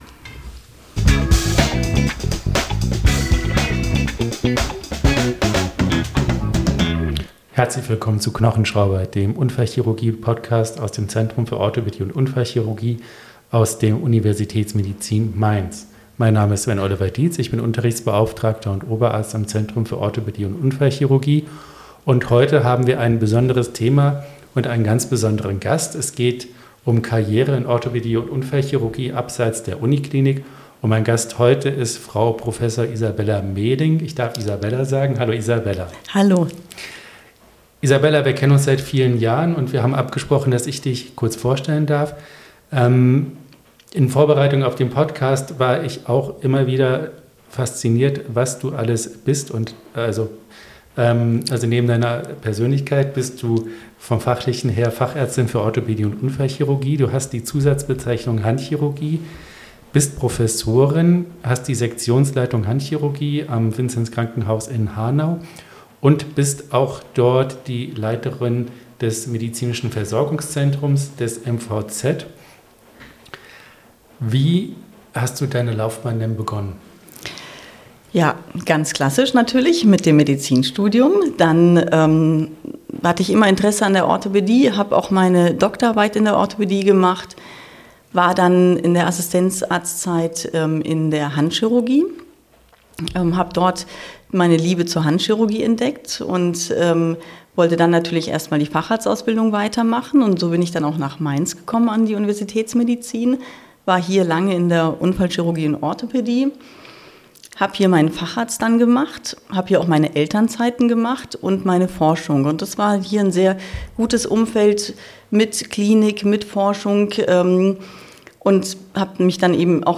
Er unterhält sich jede Woche mit einem Gast.